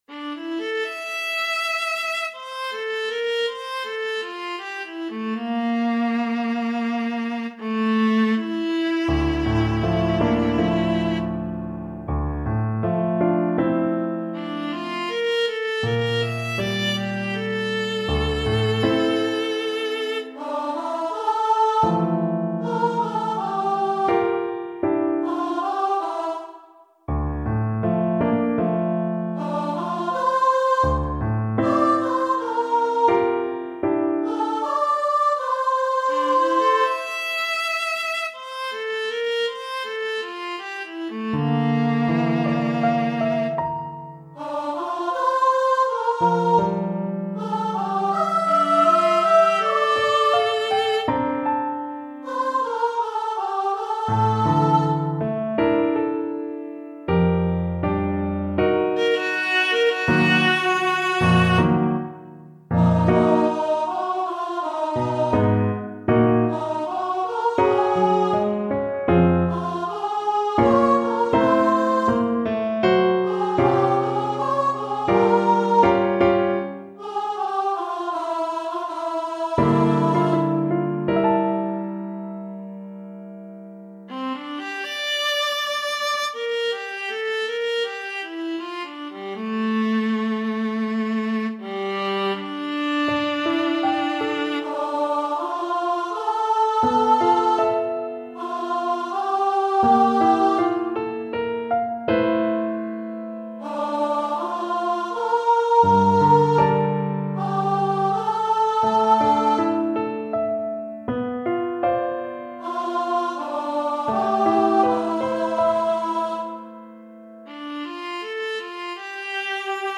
10 songs from the Tao te Ching for voice, viola, and piano